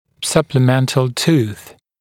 [ˌsʌplɪ’mentl tuːθ][ˌсапли’мэнтл ту:с]добавочный зуб (одна из разновидностей сверхкомплектных зубов)